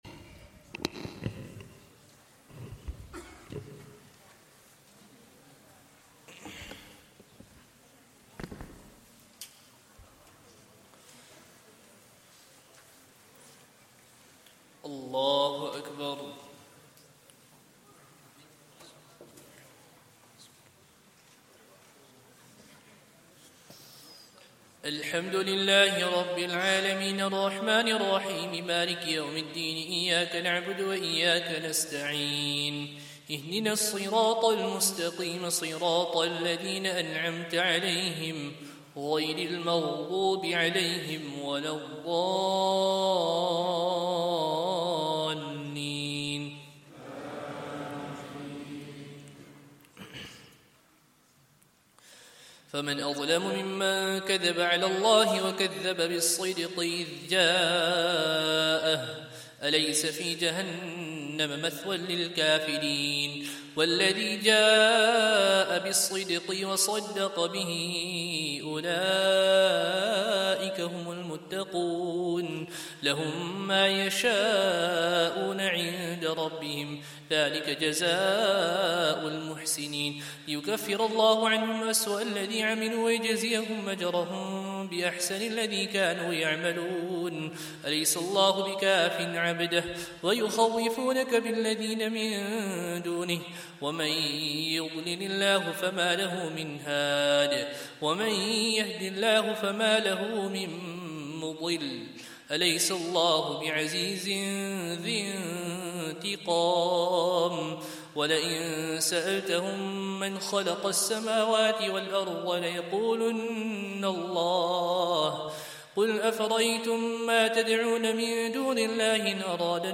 Masjid Omar | Day 20 - Taraweeh Recital - 1445 | eMasjid Live
Day 20 - Taraweeh Recital - 1445